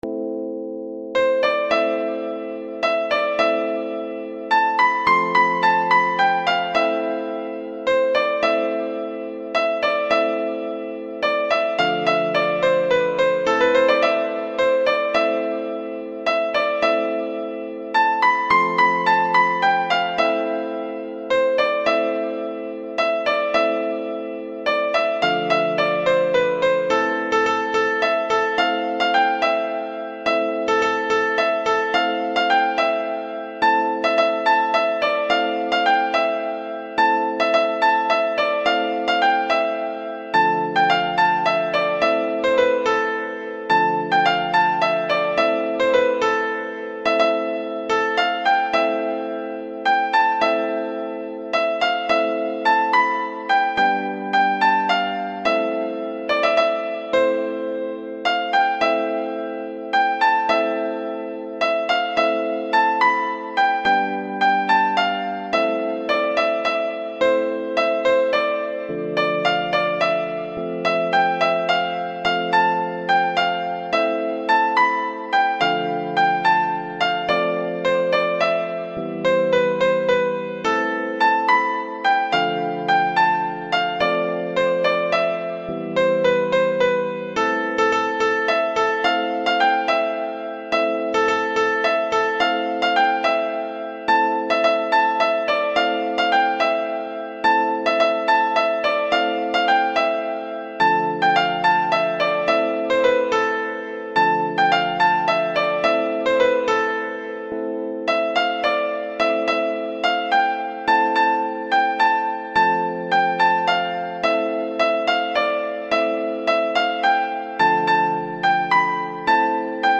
ساز : کیبورد